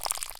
WateringCan.wav